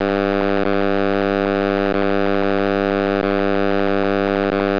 Распознавание DECT на слух (база)